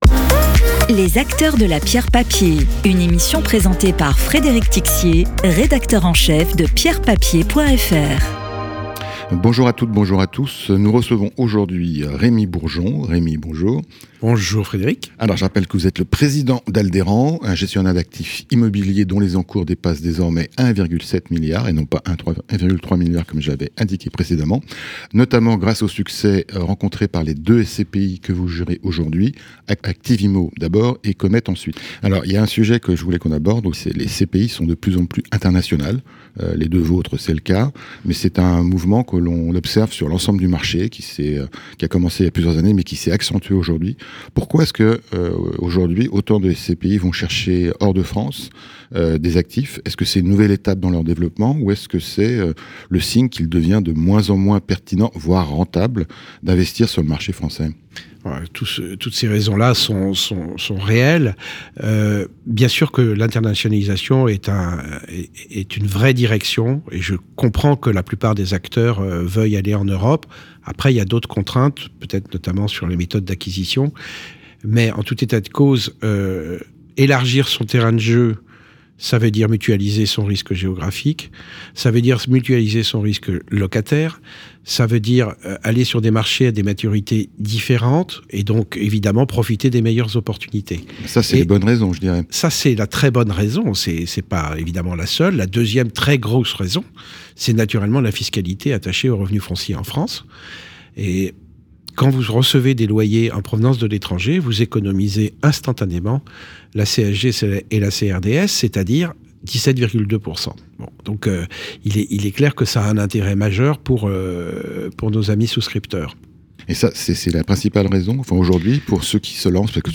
Podcast d'expert
Interview.